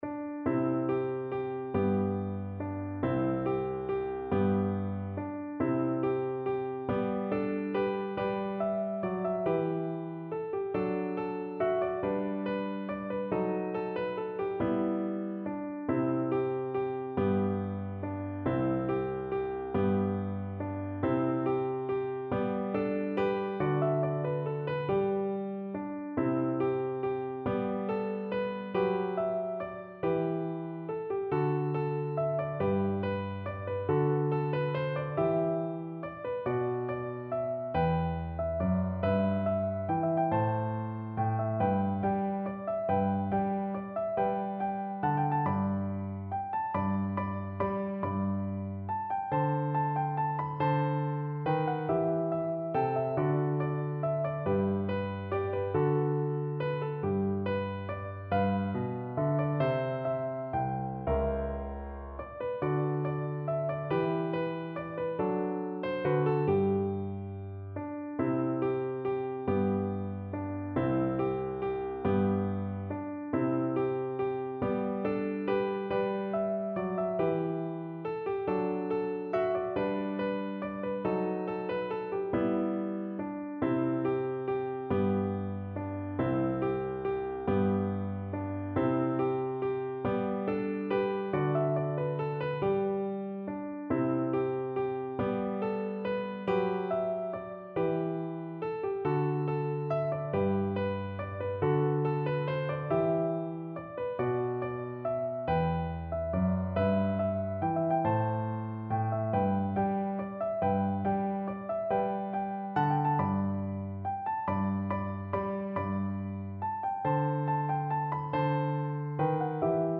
Traditional Trad. Give Me Your Hand (Irish Folk Song) Piano version
No parts available for this pieces as it is for solo piano.
G major (Sounding Pitch) (View more G major Music for Piano )
3/4 (View more 3/4 Music)
Piano  (View more Intermediate Piano Music)
Traditional (View more Traditional Piano Music)